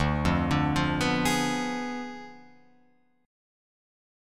Ddim7 Chord